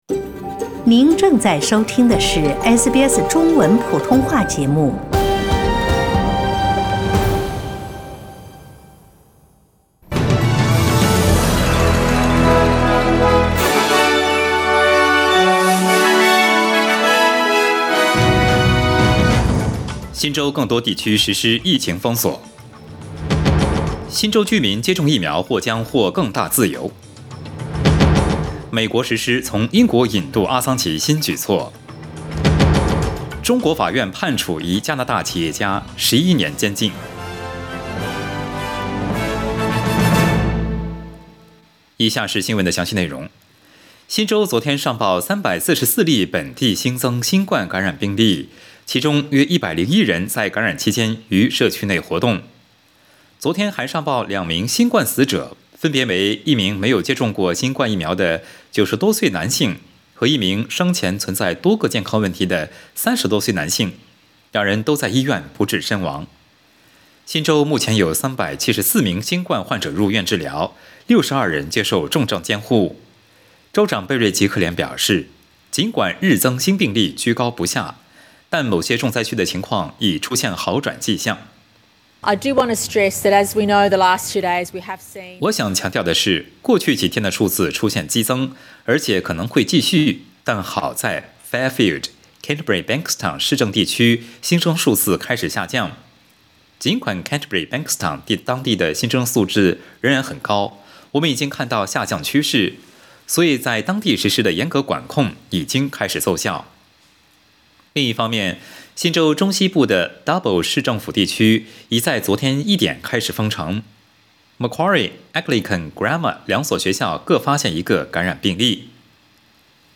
SBS早新聞（8月12日）
SBS Mandarin morning news Source: Getty Images